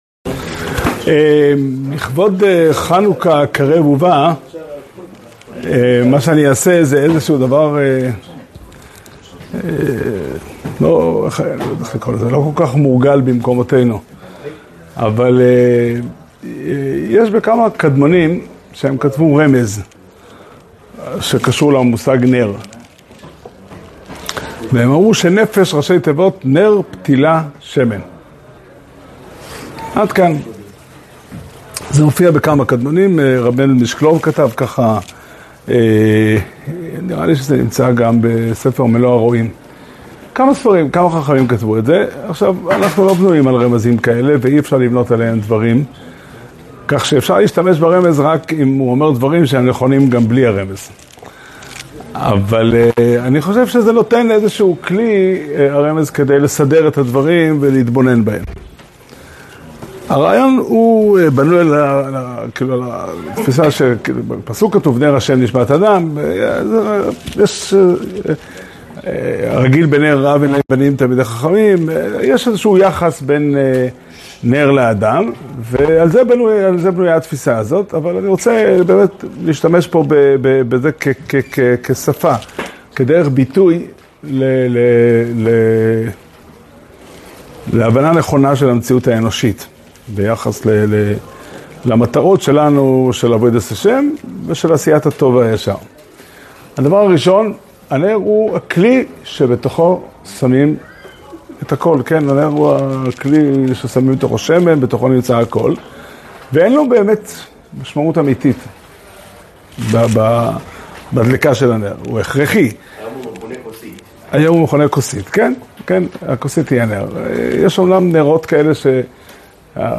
שיעור שנמסר בבית המדרש פתחי עולם בתאריך כ"ה כסלו תשפ"ה